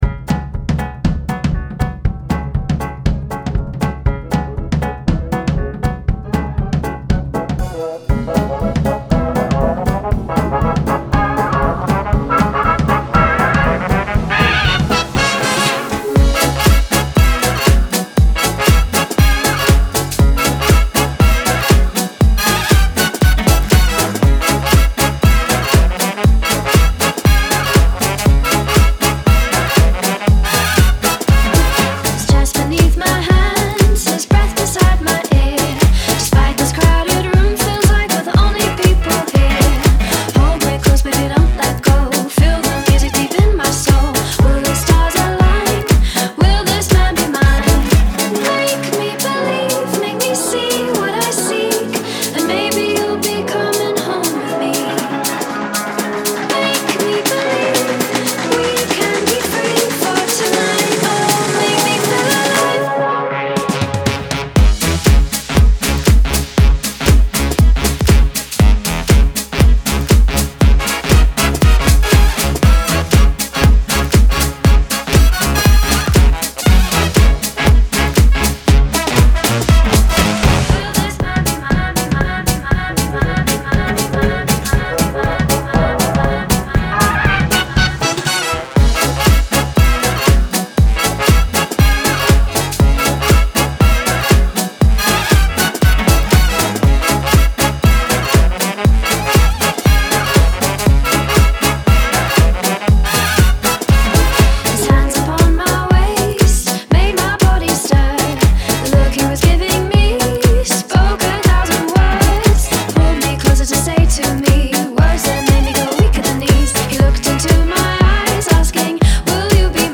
BPM119-119
Audio QualityPerfect (High Quality)
Neo Swing song for StepMania, ITGmania, Project Outfox
Full Length Song (not arcade length cut)